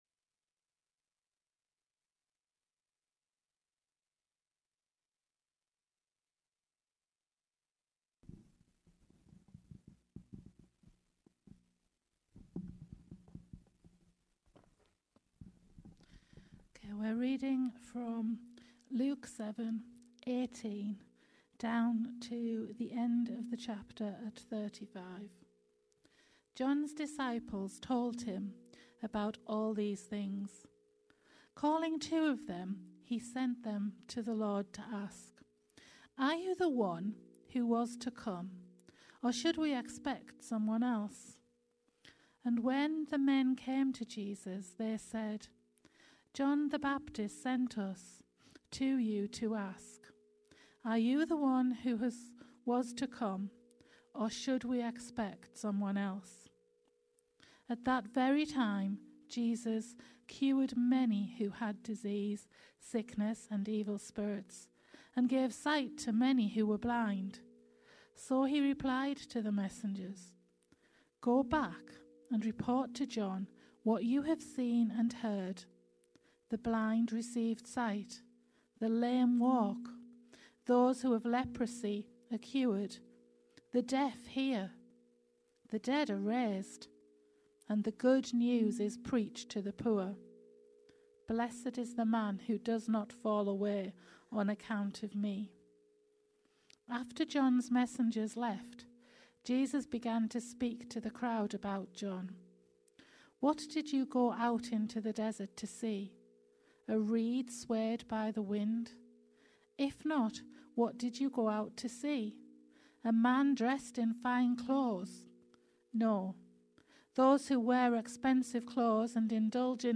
Here is the link to the bible reading being read at the beginning Luke 7:18-35